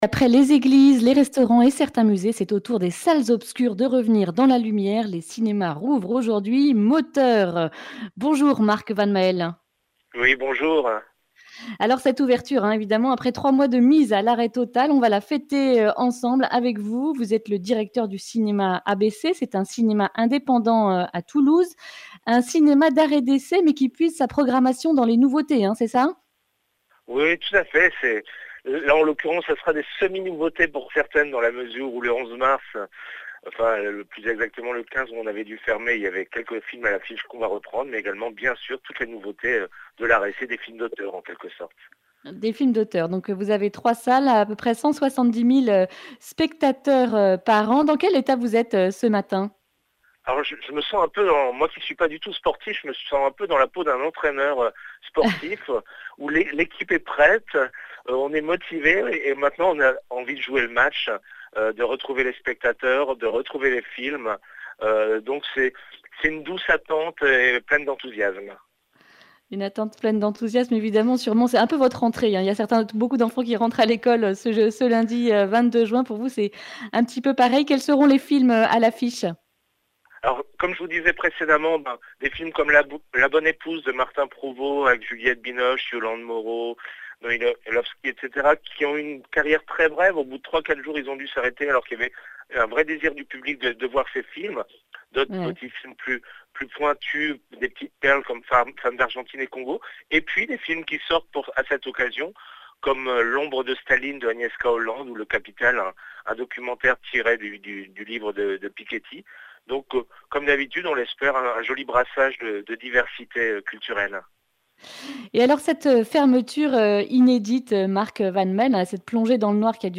Accueil \ Emissions \ Information \ Régionale \ Le grand entretien \ Tous au cinéma !